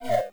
Hit6.wav